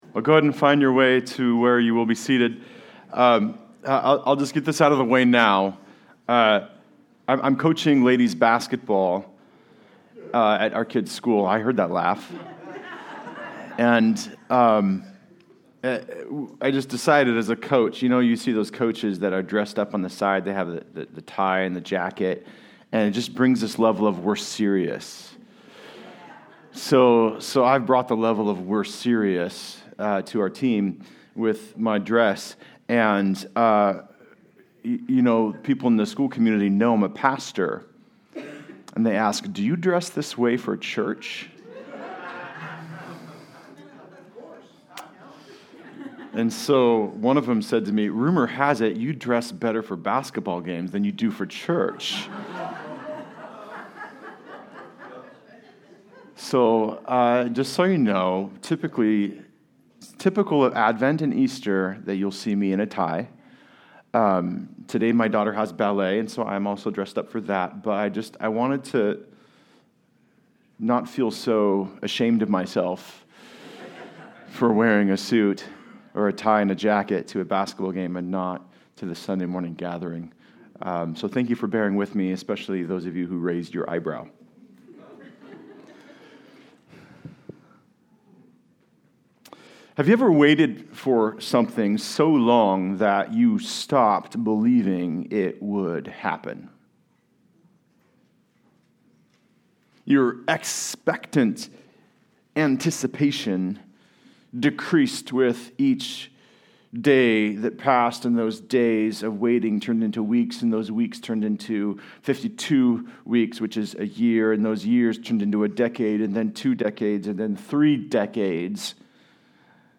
Luke 1:57-80 Service Type: Sunday Service Related « The Expectation of Faith This Thing That Has Happened…